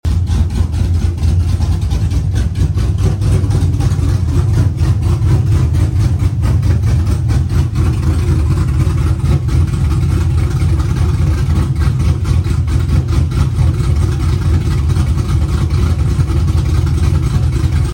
New 6.0 with a gpi ss4 cam sounding mean!